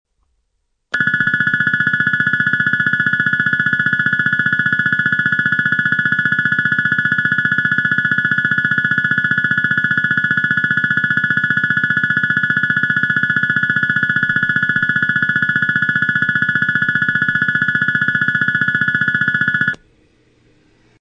接近時にカラカラベルが鳴るのみです。放送はありません。
上下線共に進入列車と被り、上り線は車両停止位置と被ります。
下り線の鳴動時間が若干長いです。
スピーカー ワイドレンジ丸型（〜現在・交換時期不明）　（TOA箱形）
3 山陽本線 厚狭・幡生・下関 方面
接近ベル ベル PCM